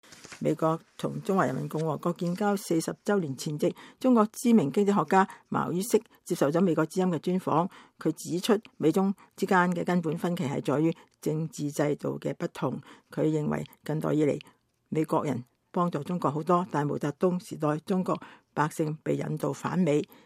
美國與中華人民共和國建交40週年前夕，中國知名經濟學家茅于軾接受了美國之音專訪。茅于軾指出，美中之間的根本分歧在於政治制度不同。